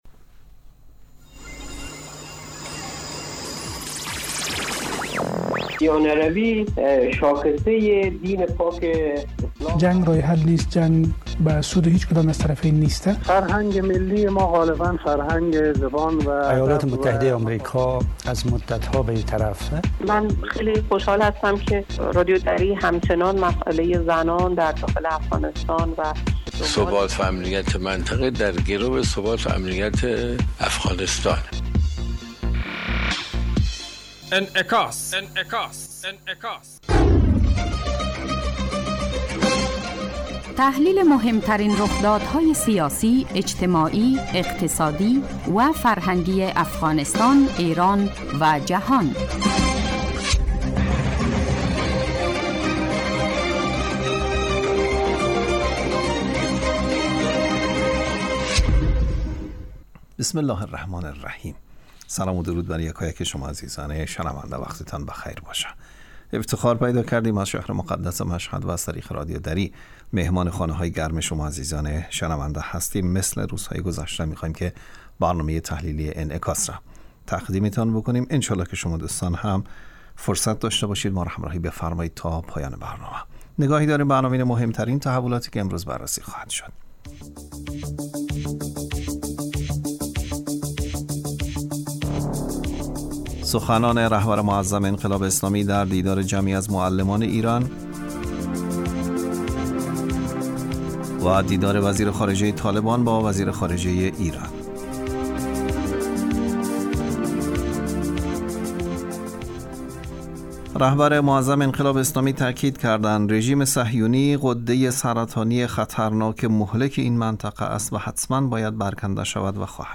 برنامه انعکاس به مدت 30 دقیقه هر روز در ساعت 12:30 ظهر (به وقت افغانستان) بصورت زنده پخش می شود.